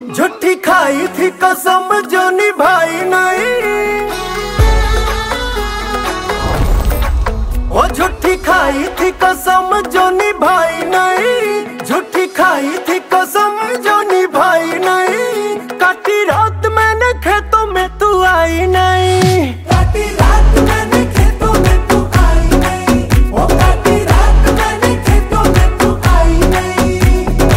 Categories: Bollywood Ringtones